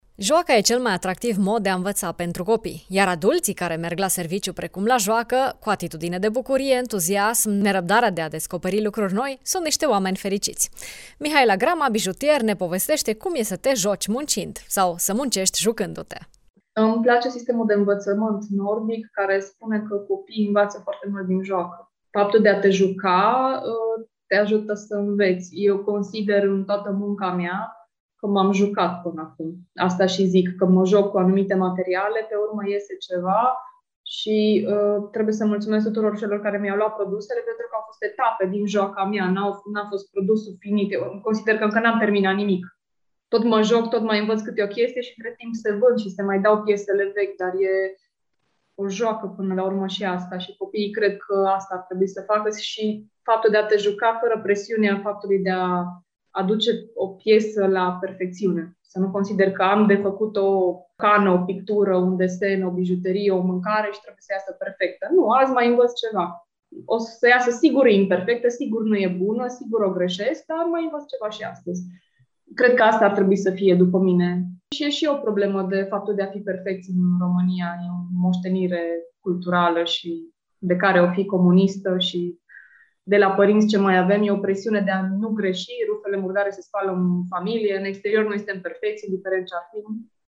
bijutier